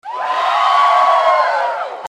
Crowd Cheering
Crowd_cheering.mp3